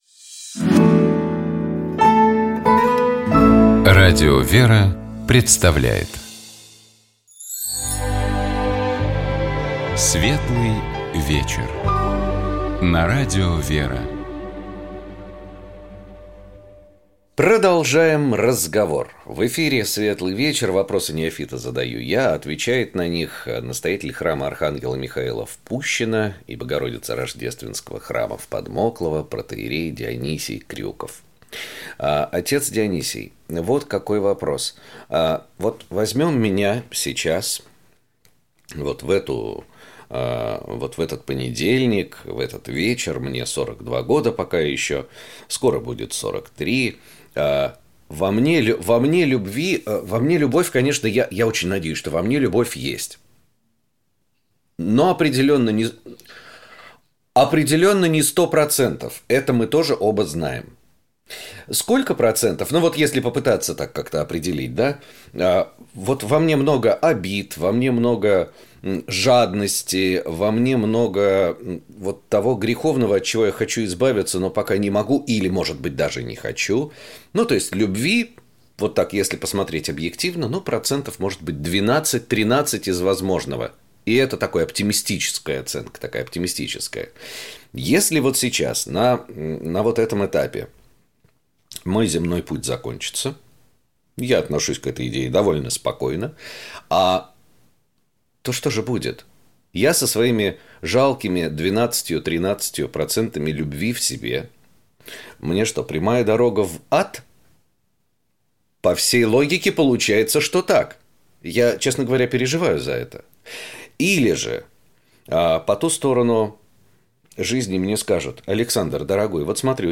Также разговор шел о том, могут ли наши домашние животные попасть в рай.